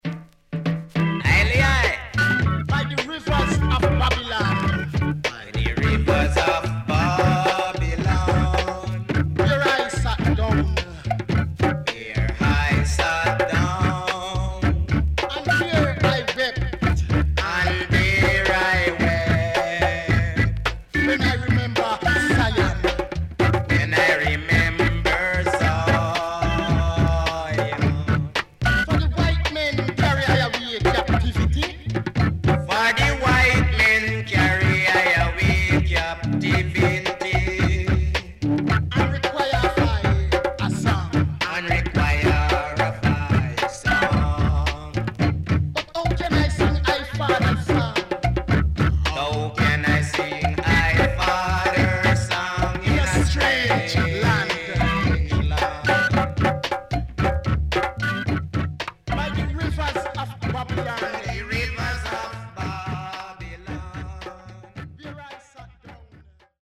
HOME > Back Order [VINTAGE 7inch]  >  EARLY REGGAE
SIDE A:少しチリノイズ入ります。